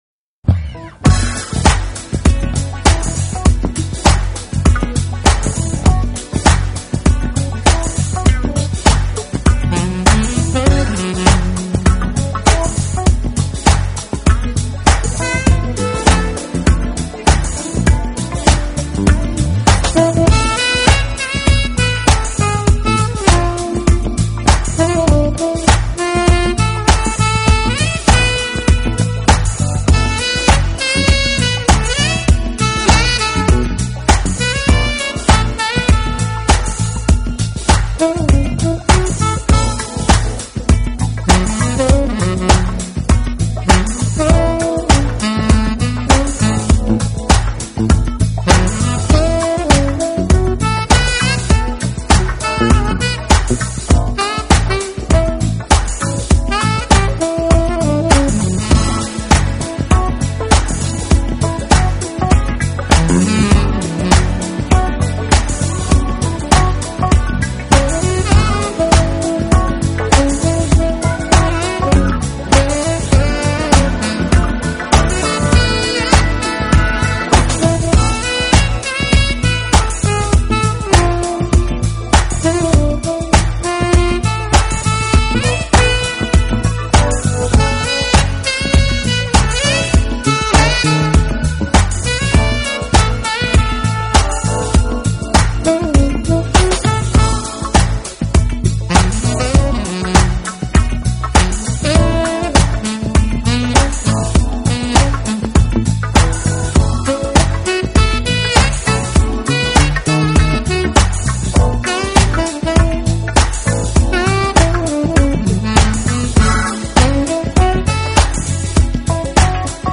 音乐类型: Smooth Jazz